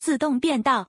audio_lane_change.wav